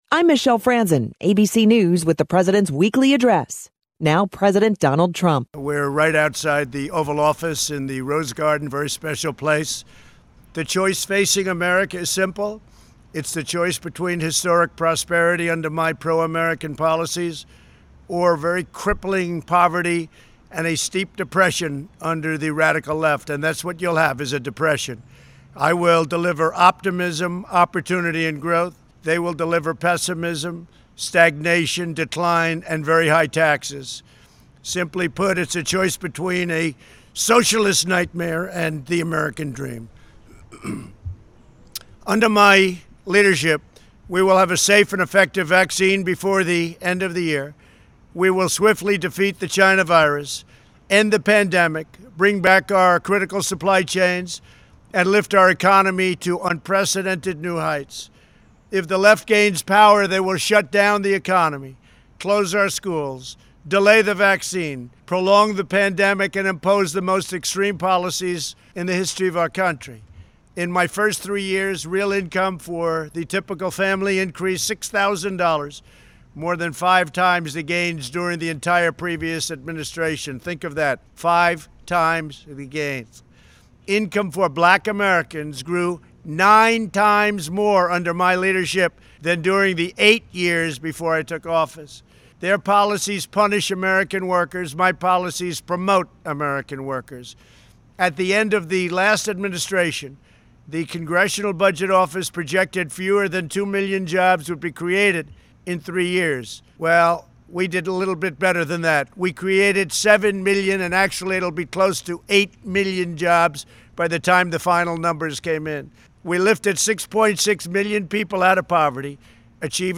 Trump was Monday’s KVML “Newsmaker of the Day”.